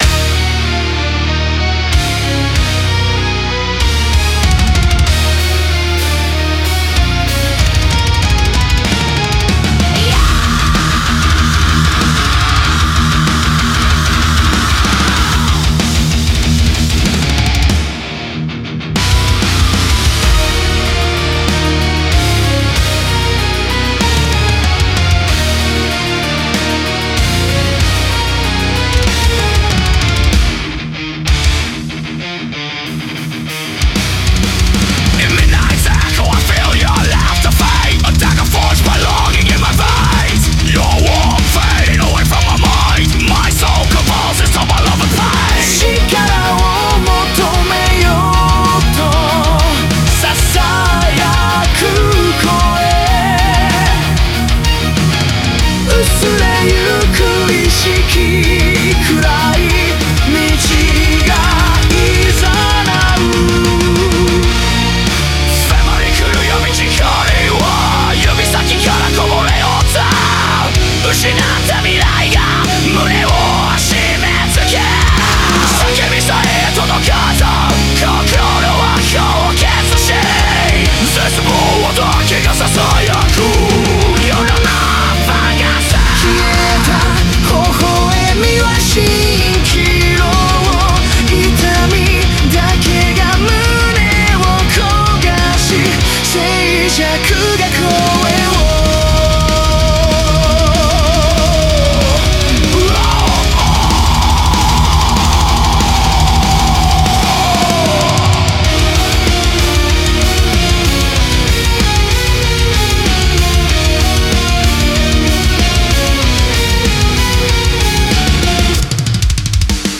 Symphonic Death Metal
• 2026-01-16 Remastered